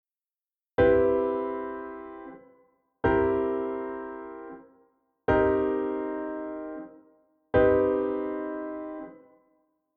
Here are the chords laid out for you so you can hear the difference and see the notational difference…